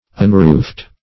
Unroofed \Un*roofed"\, a.